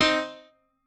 piano4_10.ogg